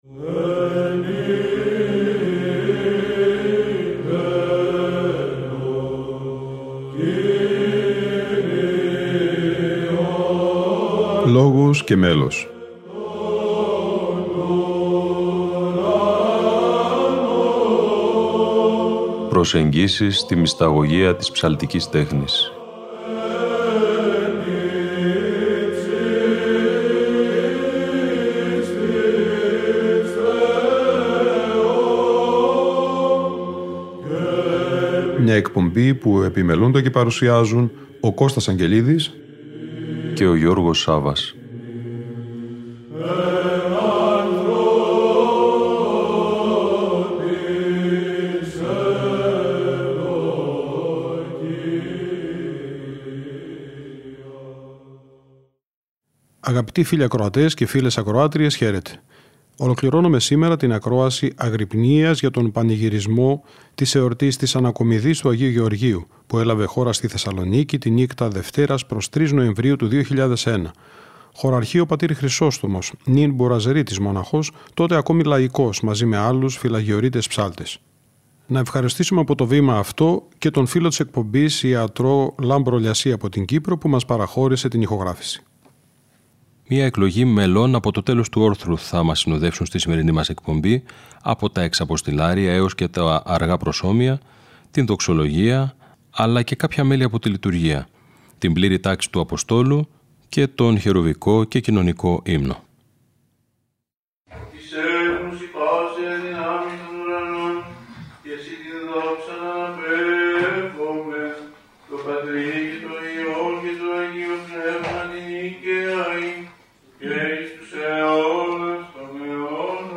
Αγρυπνία Ανακομιδής Λειψάνων Αγίου Γεωργίου - Ροτόντα 2001 (ΣΤ΄)